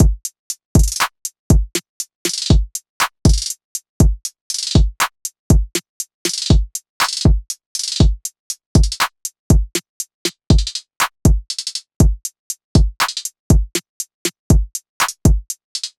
SOUTHSIDE_beat_loop_swiss_full_120.wav